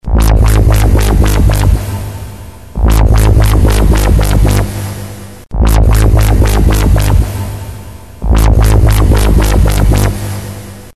Jungle Loop 3